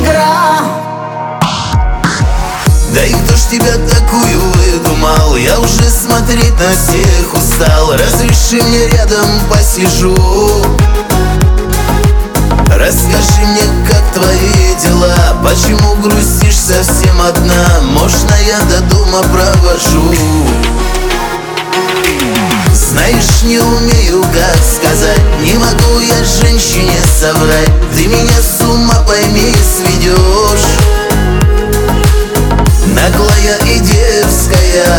Жанр: Русская поп-музыка / Русские